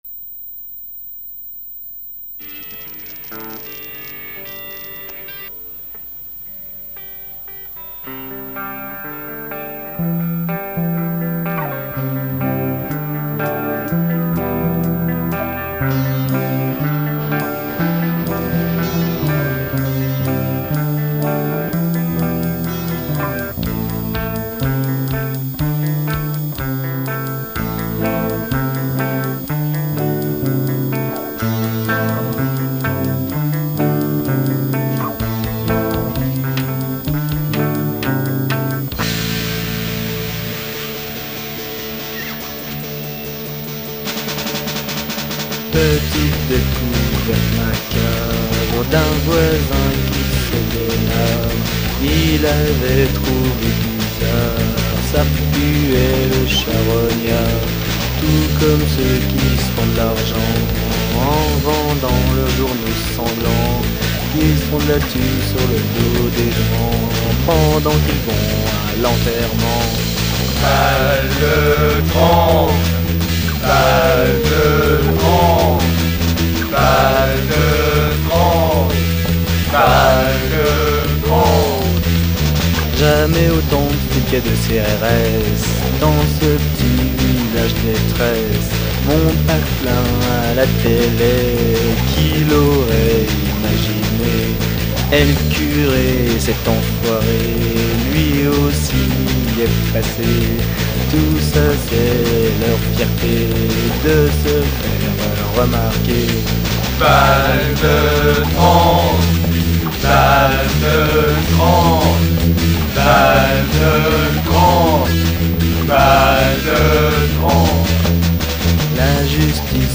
Guitares
Batterie électronique
Basse
Coeurs : les habitués du squat de Saint Etienne